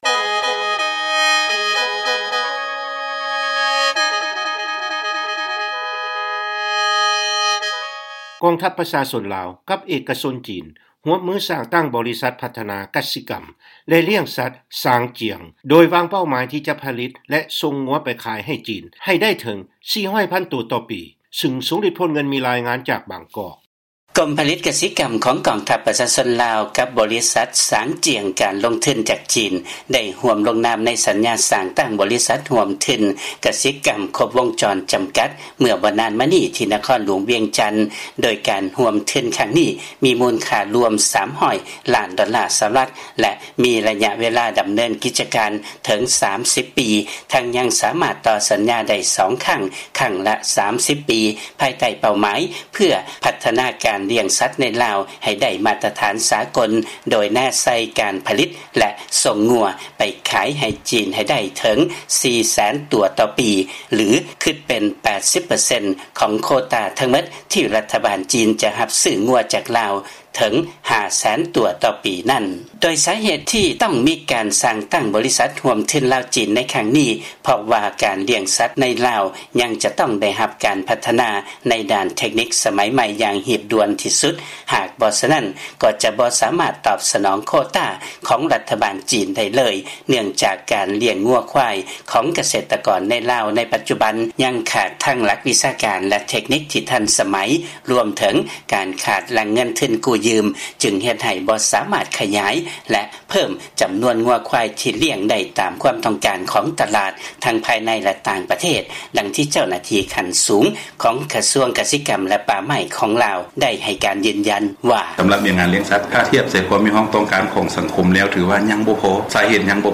ຟັງລາຍງານ ກອງທັບປະຊາຊົນ ລາວ ກັບເອກະຊົນ ຈີນ ຮ່ວມທຶນສ້າງຕັ້ງ ບໍລິສັດພັດທະນາ ກະສິກຳ ແລະ ລ້ຽງສັດຄົບວົງຈອນ